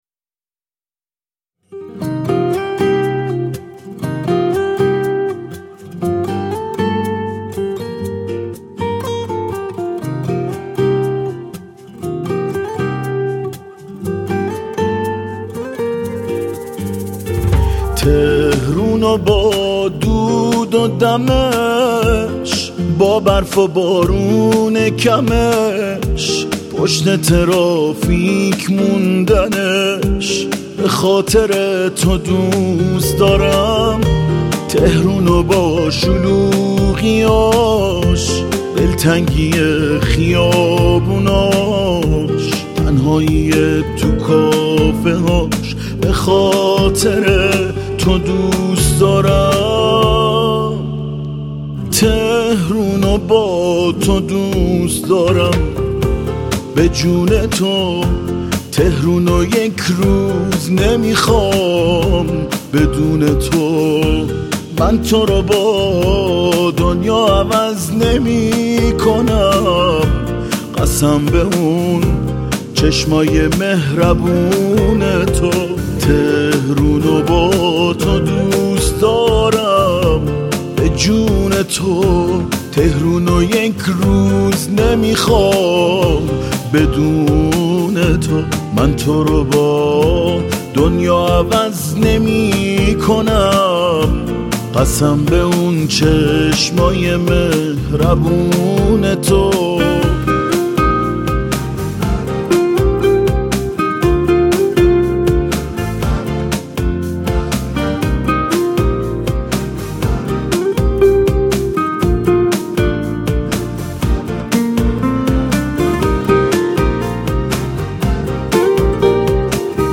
عاشقانه